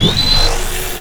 shard.wav